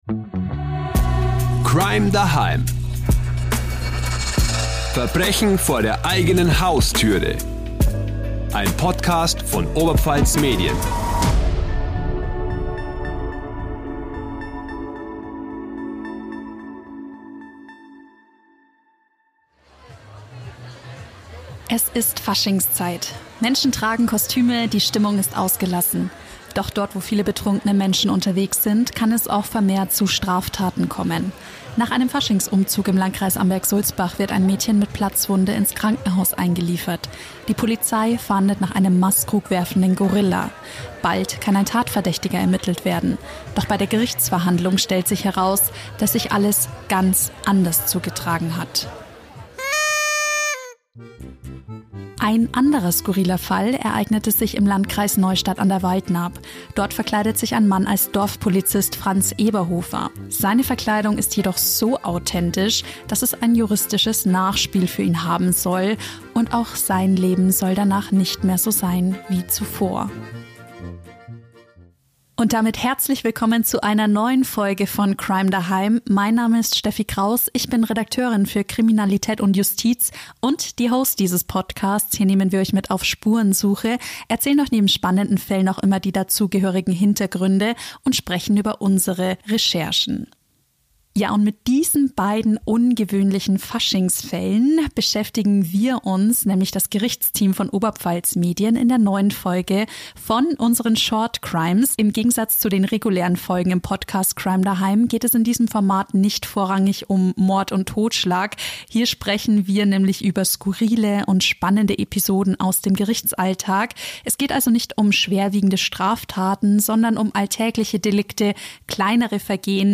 Hier sprechen die Redakteure über skurrile und spannende Episoden aus dem Gerichtsalltag.